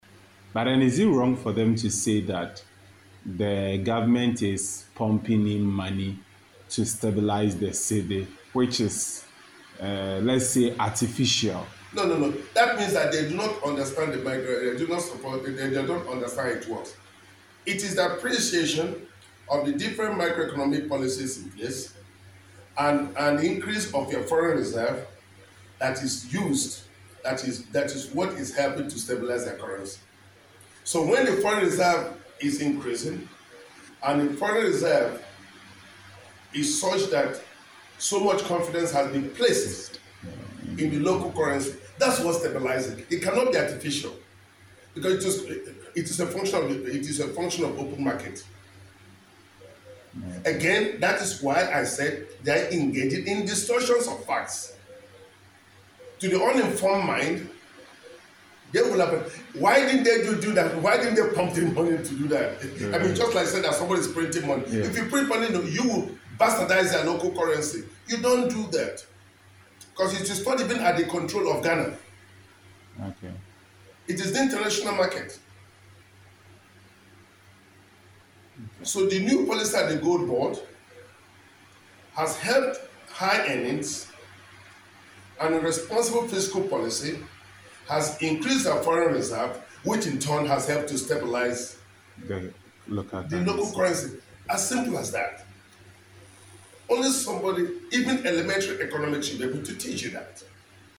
News Politics